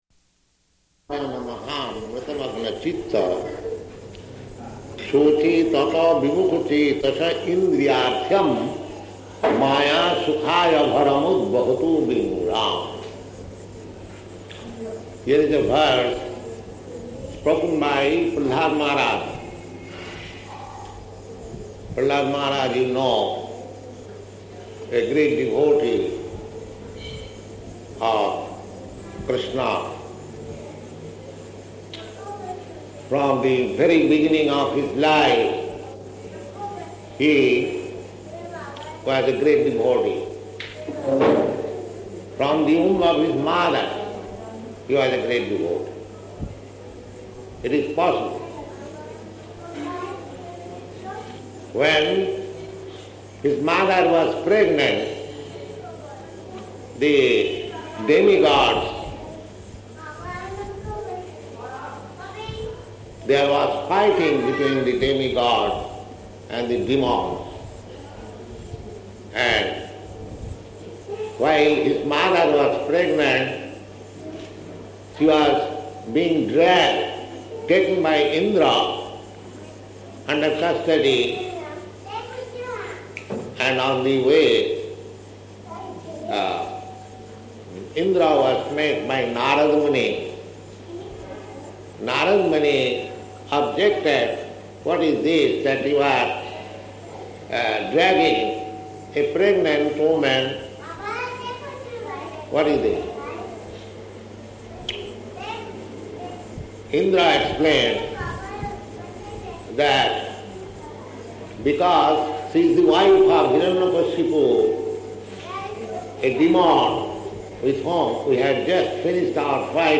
Location: Visakhapatnam